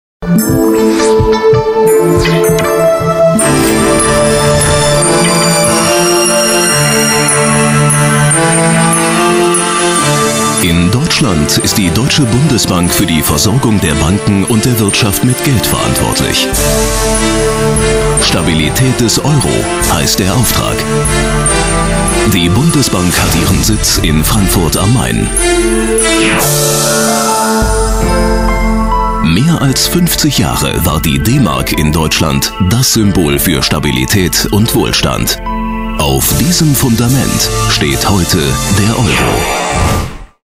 Gänsehautstimme für Werbung, Imagefilm, Station Voice uvm.
Kein Dialekt
Sprechprobe: eLearning (Muttersprache):